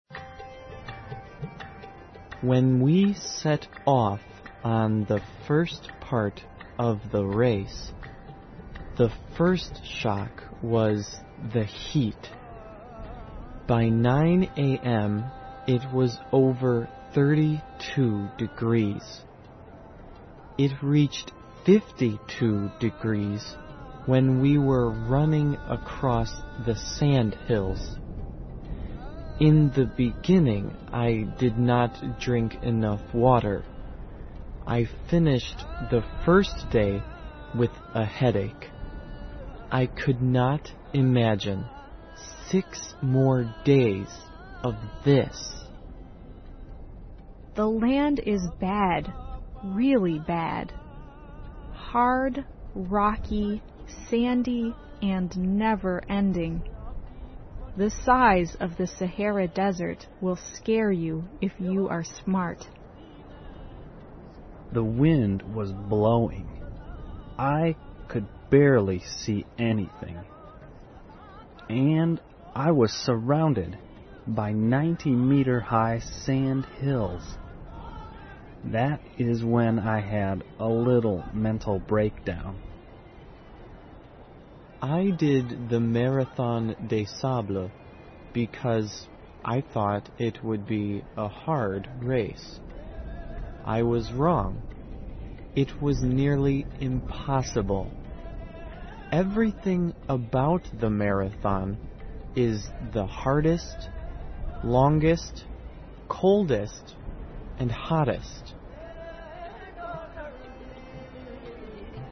环球慢速英语 第112期:撒哈拉沙漠马拉松(6)